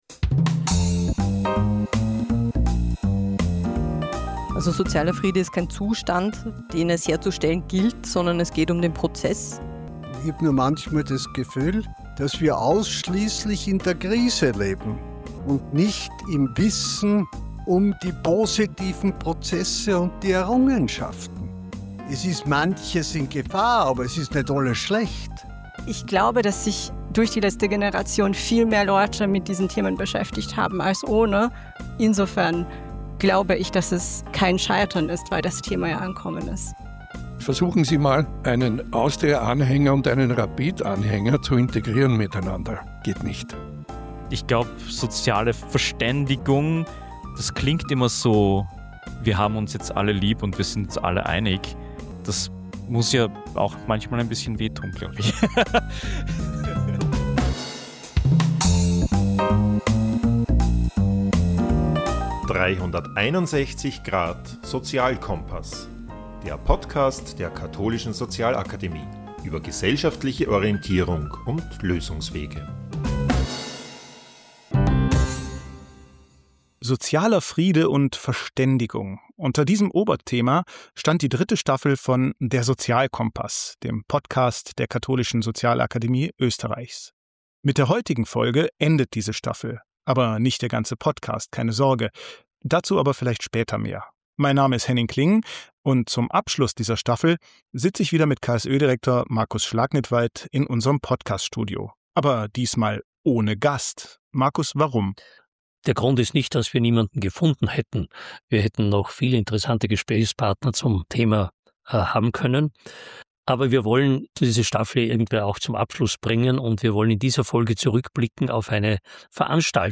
diskutierten beim "ksœ-Forum" über den Zusammenhang von sozialem Frieden und Verständigung.